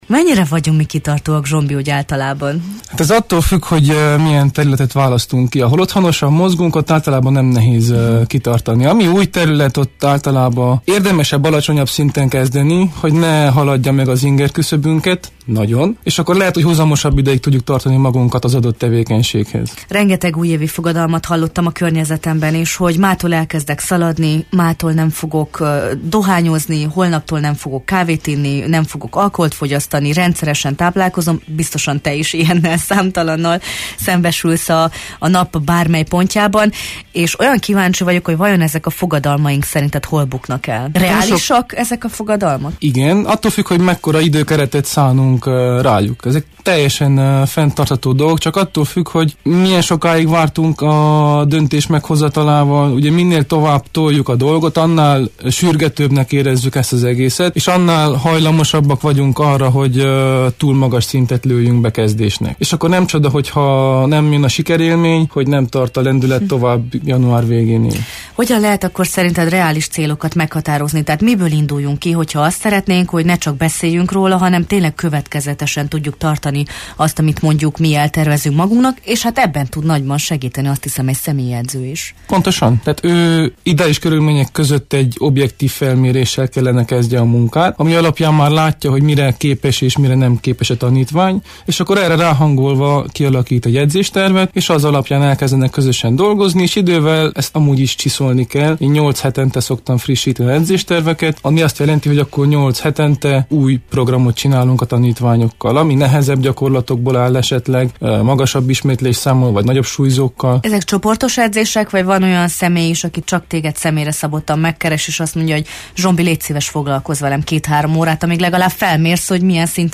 Új év, új lehetőségek a mozgásra! - személyi edzőt kérdeztünk - Marosvasarhelyi Radio